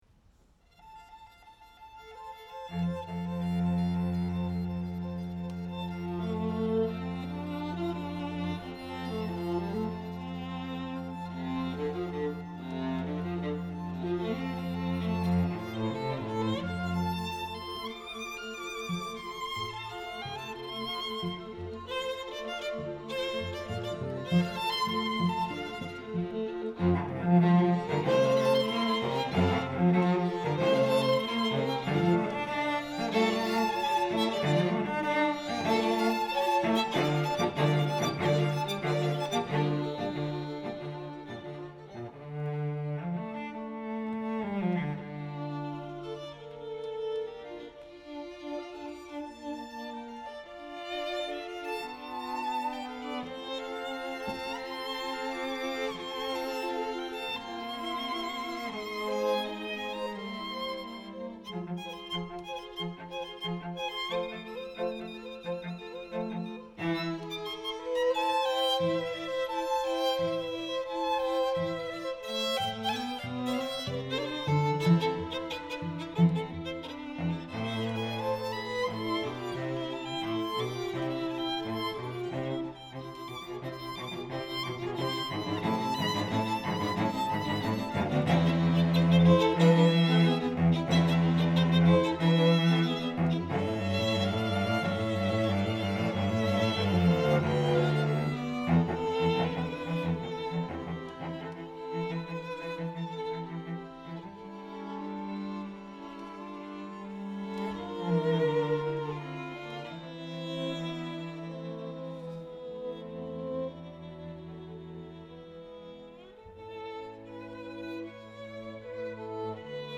Chamber Groups
Allegro ma non troppo